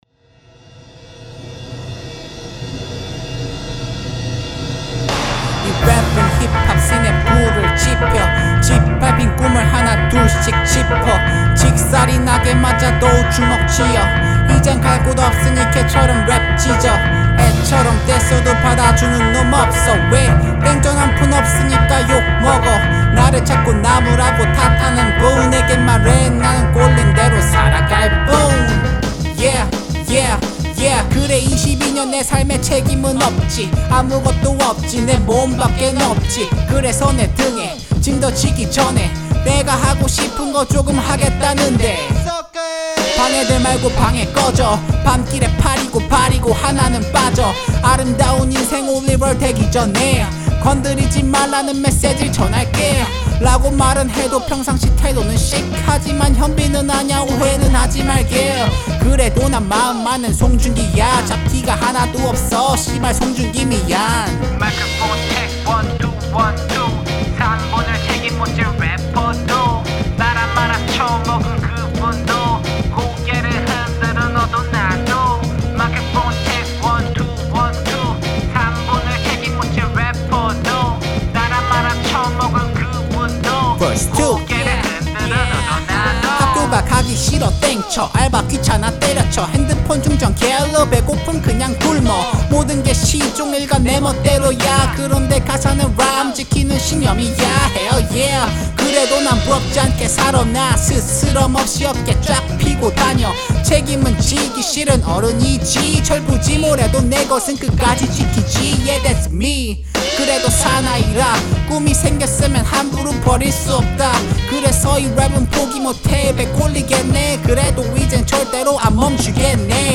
샘플 컷페이스트 부터 녹음까지 다 제가했구요